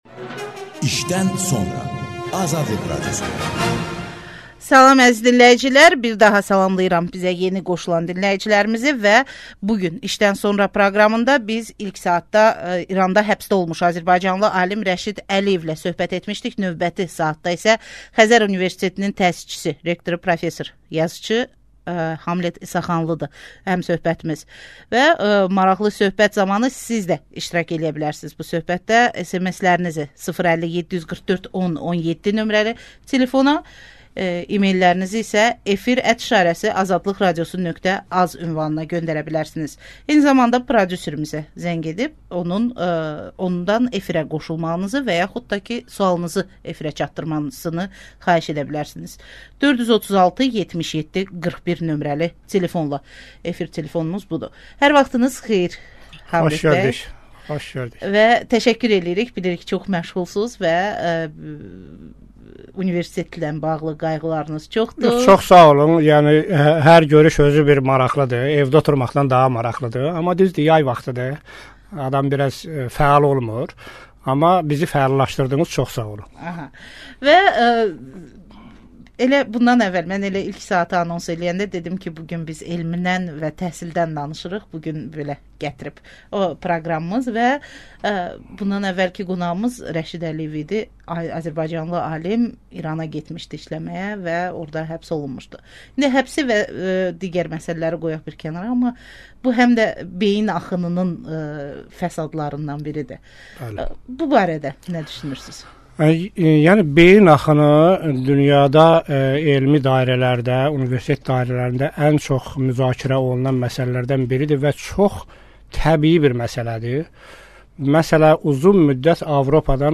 Hamlet İsaxanlı ilə söhbət